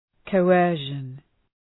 Προφορά
{kəʋ’ɜ:rʃən}
coercion.mp3